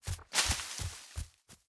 sfx_bunny_scurry_v1.ogg